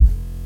drums and loops » kick off
描述：loop beat drum
标签： drum kick
声道立体声